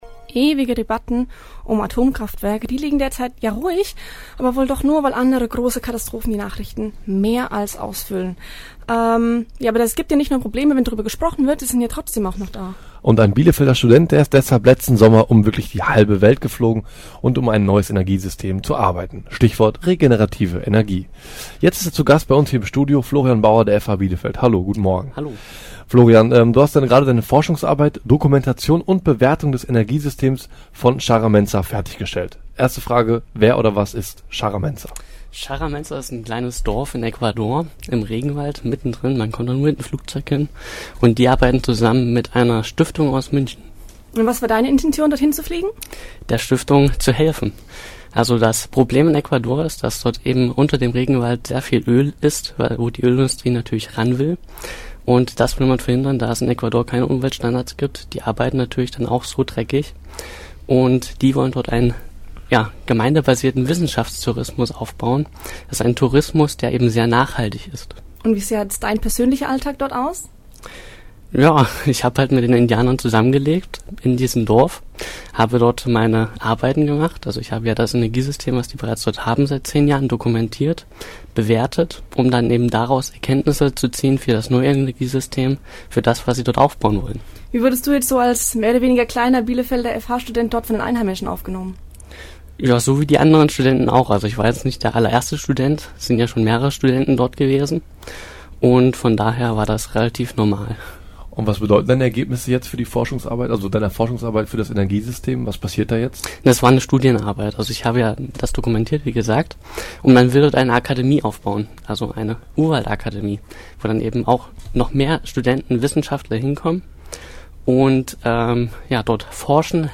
Interview als MP3-Datei